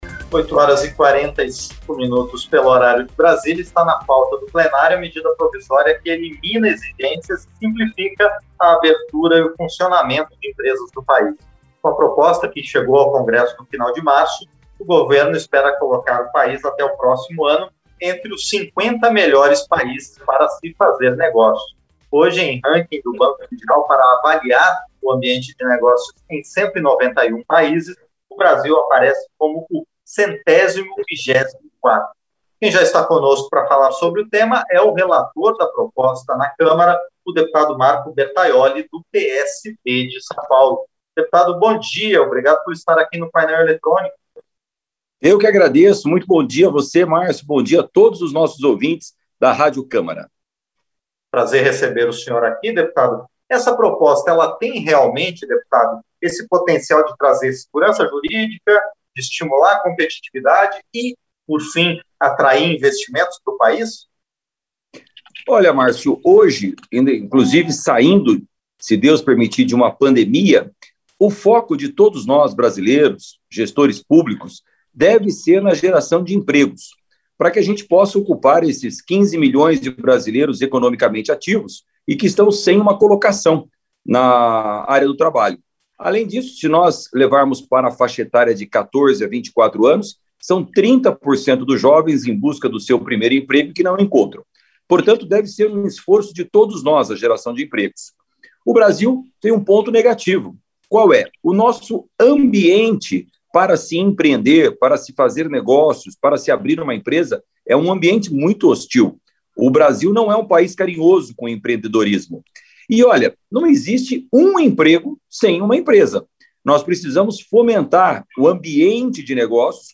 Entrevista - Dep. Marco Bertaiolli (PSD-SP)